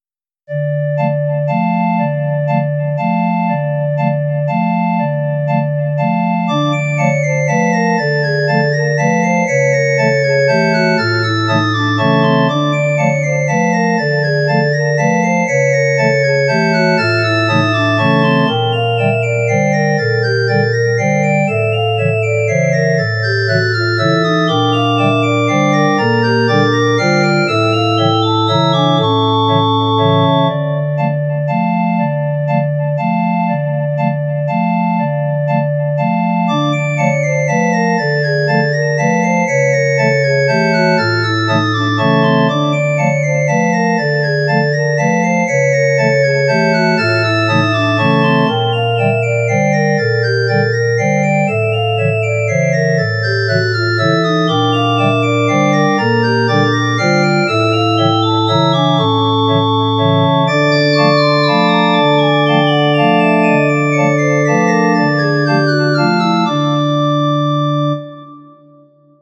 ●蒸気オルガン
サーカスのBGMを奏でていた。